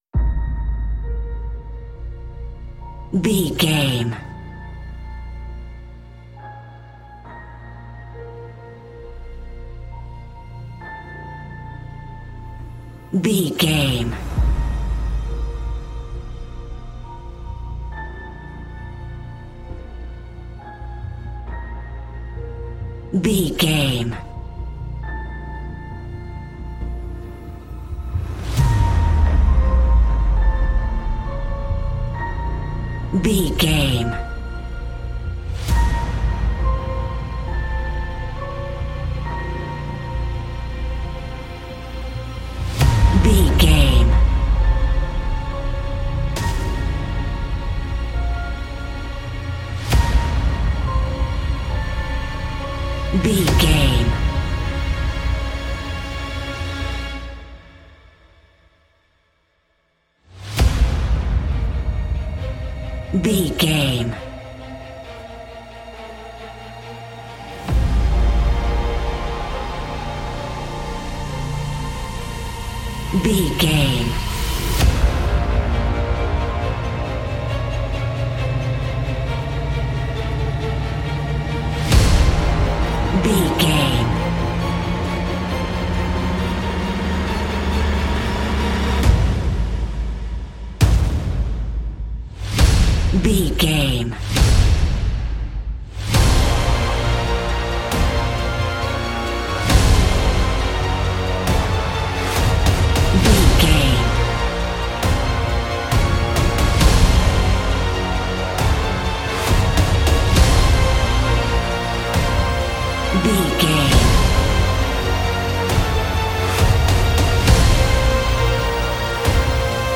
Epic / Action
Fast paced
In-crescendo
Aeolian/Minor
B♭
eerie
dark
synthesiser
piano
cello